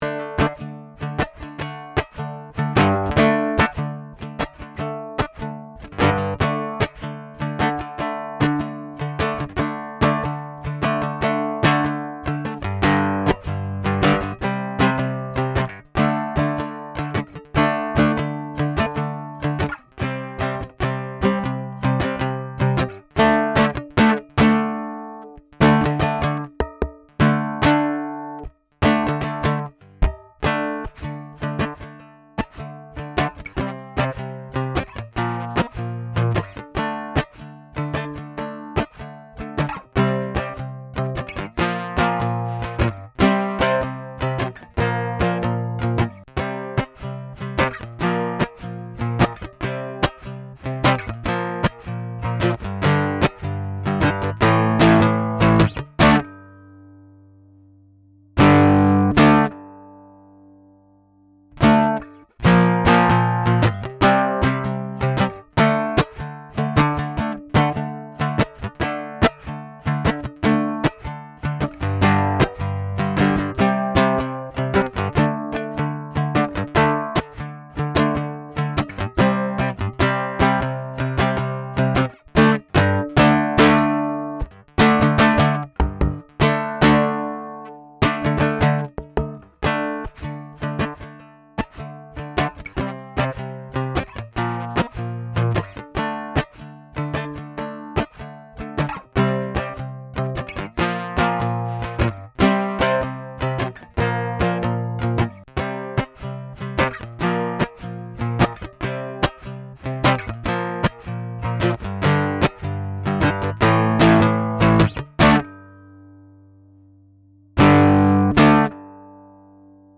voici le playback pour s’entraîner avant le grand jour du spectacle.
Bol-bleu-et-saladier-rose-CM1_playback.mp3